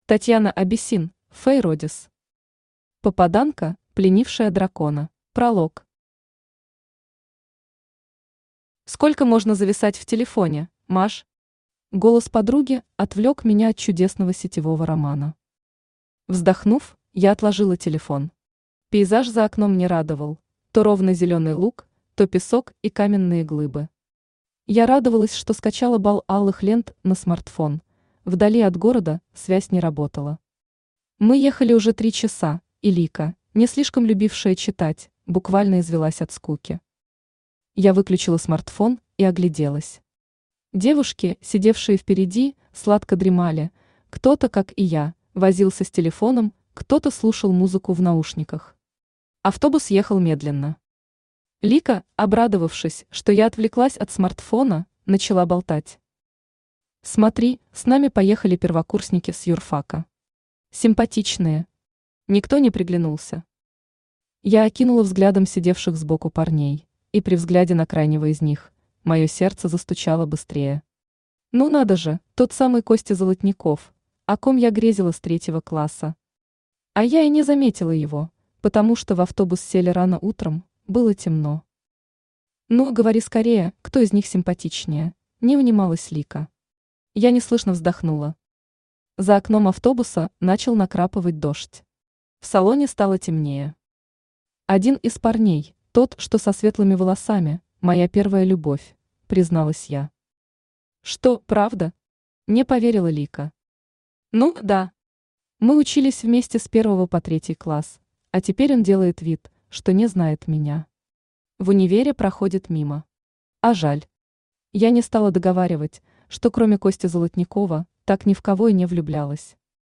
Аудиокнига Попаданка, пленившая дракона | Библиотека аудиокниг
Aудиокнига Попаданка, пленившая дракона Автор Татьяна Абиссин Читает аудиокнигу Авточтец ЛитРес.